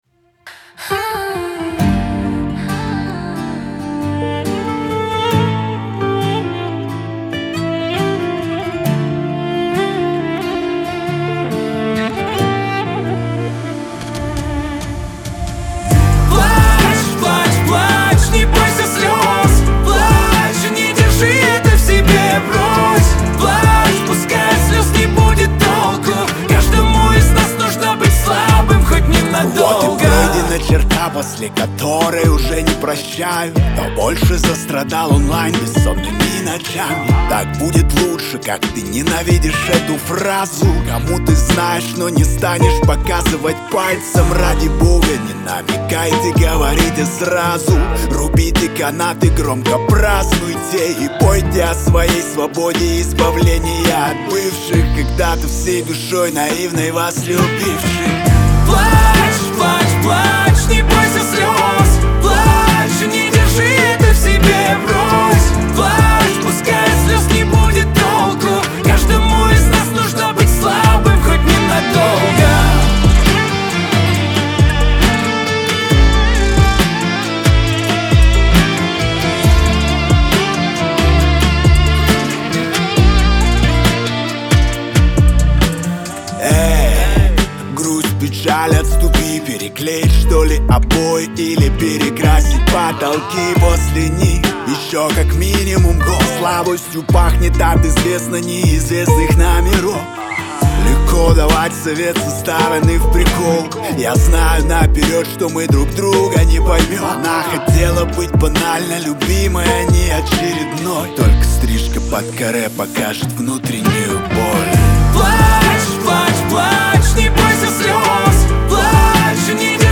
ХАУС-РЭП
грусть , эстрада